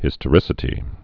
(hĭstə-rĭsĭ-tē)